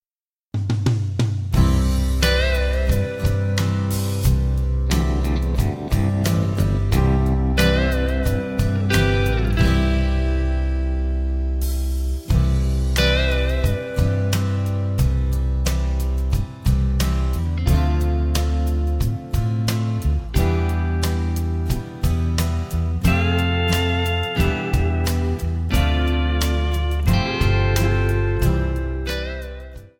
Backing track Karaoke
Country, 2000s